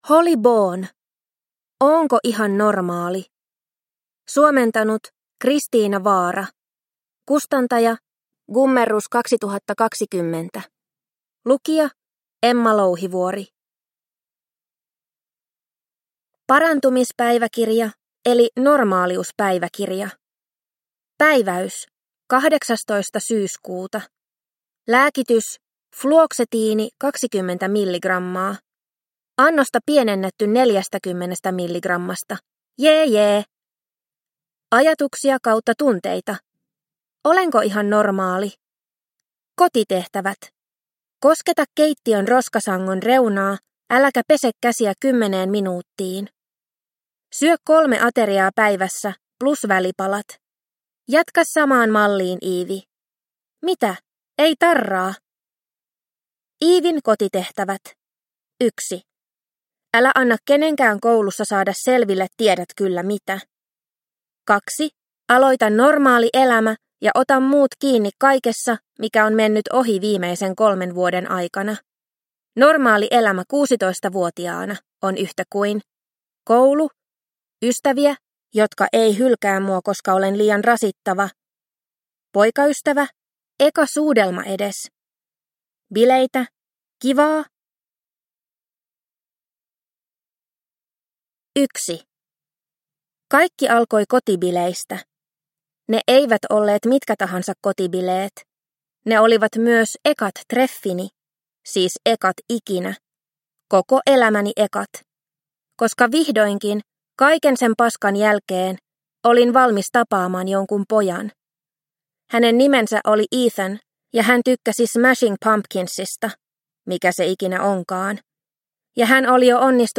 Oonko ihan normaali? – Ljudbok – Laddas ner